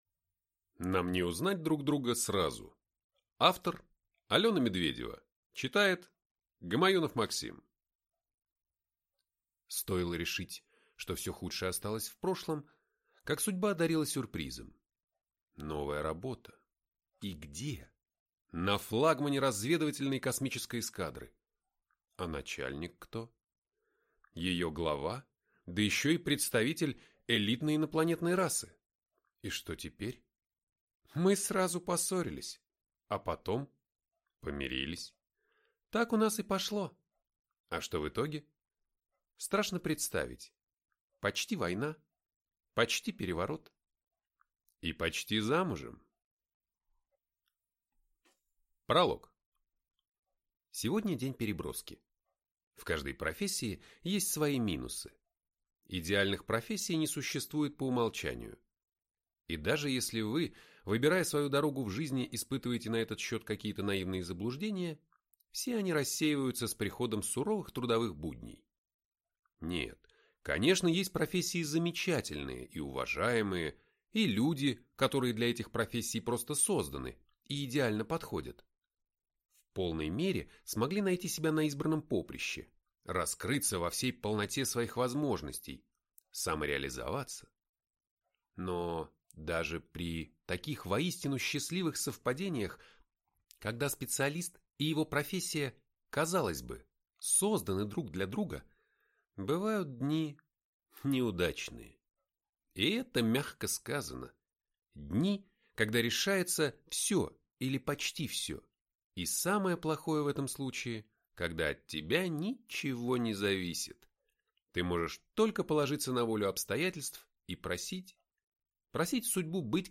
Аудиокнига Нам не узнать друг друга сразу | Библиотека аудиокниг